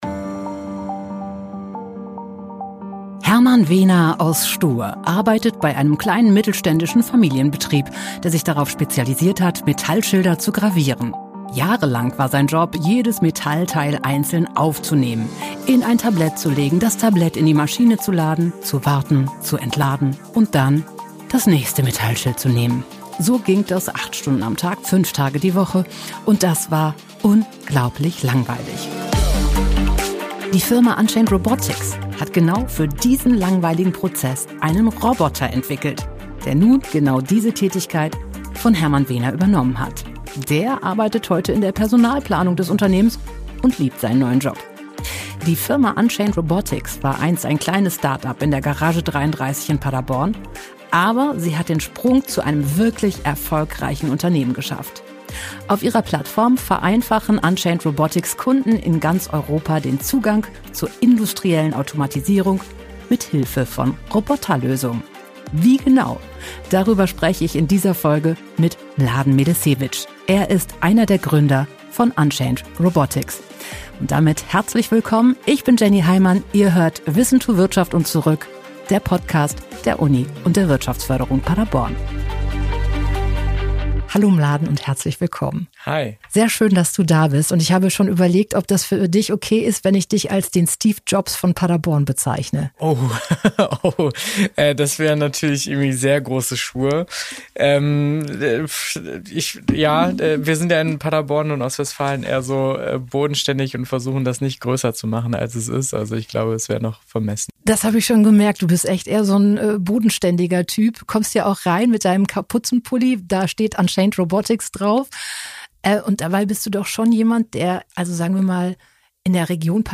Ein Gespräch über Software, Mut, Bodenständigkeit – und warum Ostwestfalen ein echter Innovationsstandort ist.